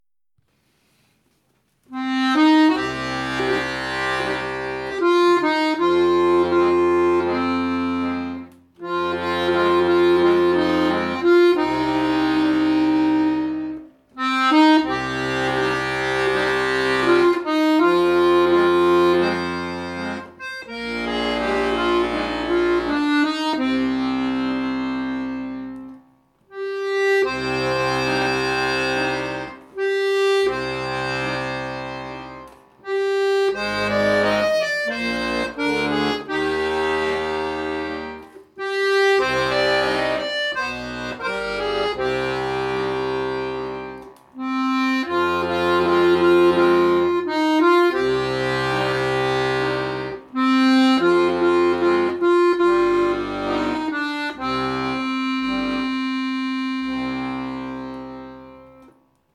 Diese Runde läuft unter dem Motto "Alles nur geklaut"!? Man nehme: eine populäre Opernouverture von einem längst verstorbenen Komponisten schneide alles Sperrige heraus füge den Rest zusammen unterlege ihn mit einem Text und lässt das Ganze von einem Schmusesänger interpretieren.